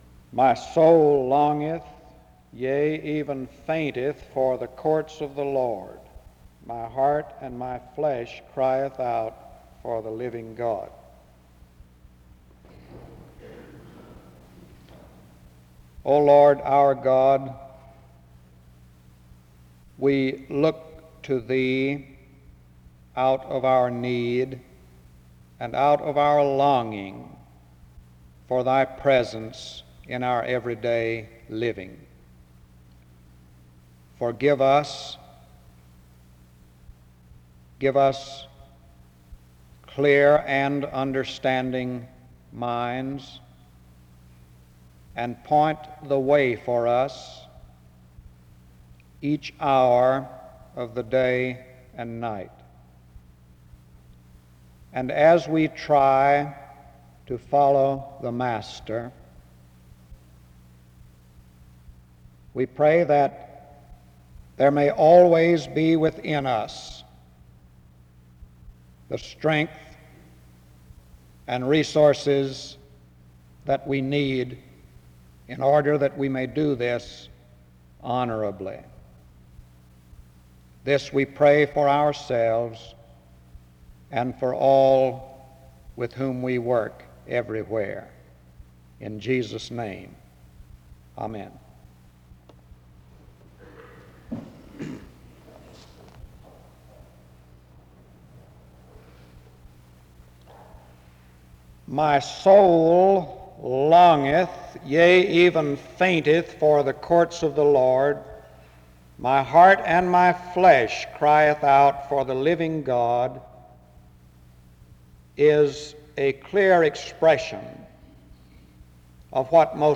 The service starts with a scripture reading and prayer from 0:00-1:31.